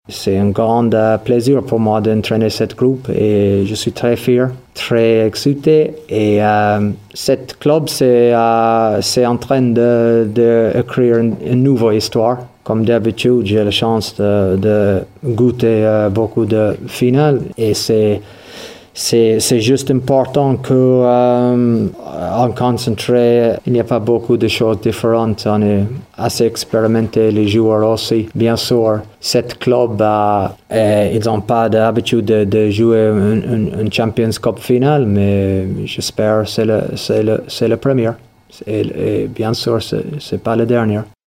Ils se sont entraînés pour cela, avec constance et régularité. On écoute le coach Ronan O’Gara, très fier du travail accompli :